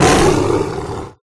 Monster9.ogg